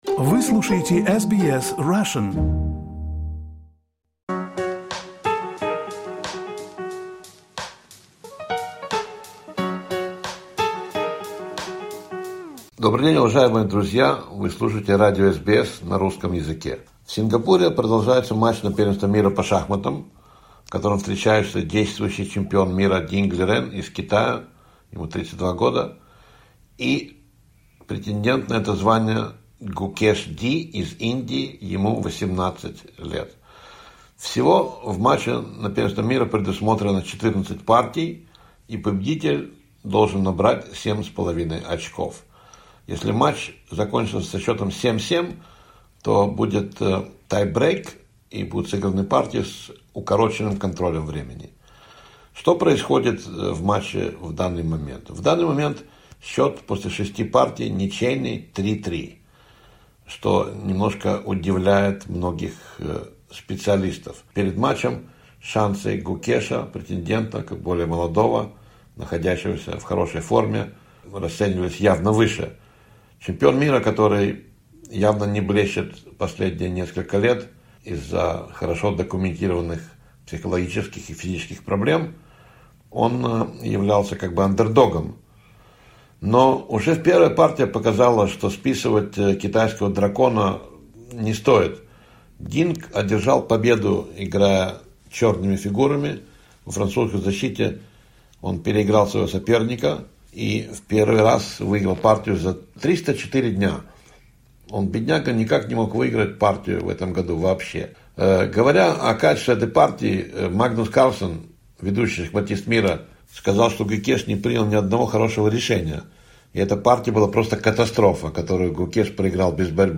Спортивный обзор: Кто станет чемпионом мира по шахматам 2024?